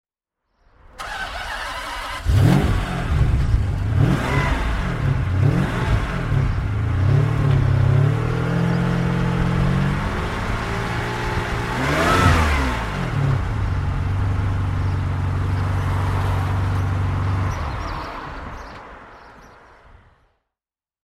Ferrari 550 Maranello (1997) - Starten und Leerlauf